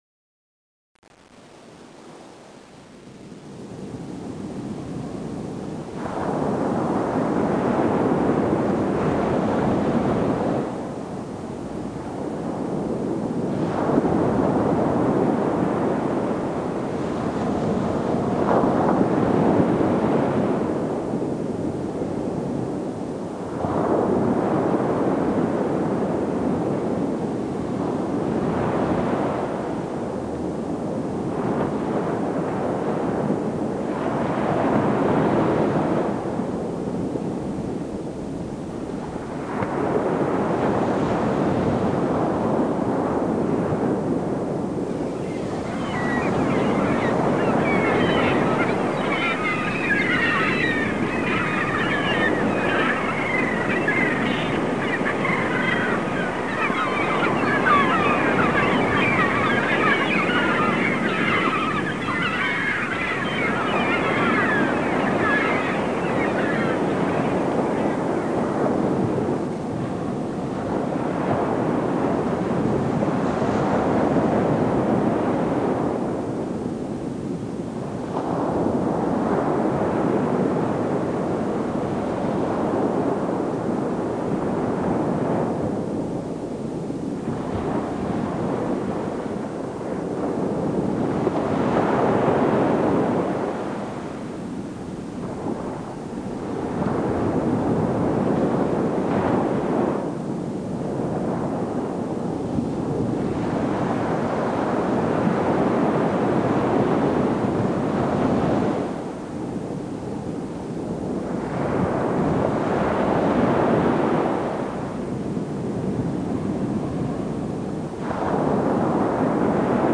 1 channel
waves1.mp3